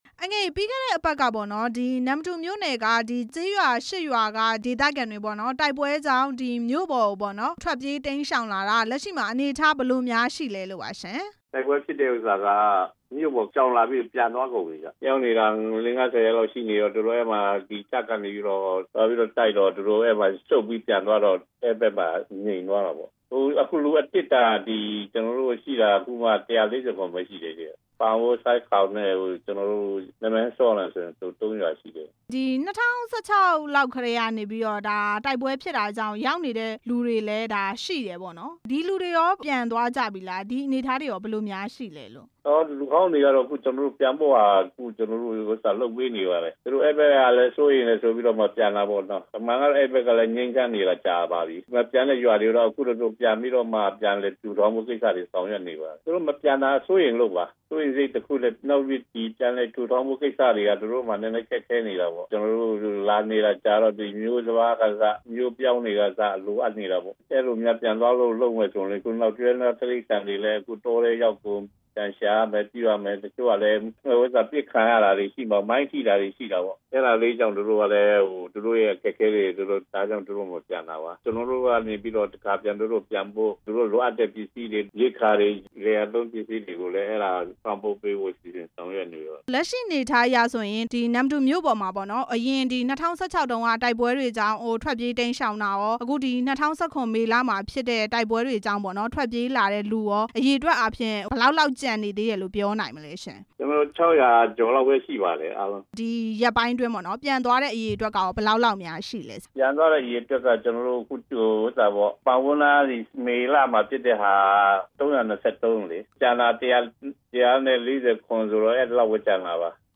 နမ္မတူမြို့ပေါ်က စစ်ဘေးဒုက္ခသည်တွေ အခြေအနေ မေးမြန်းချက်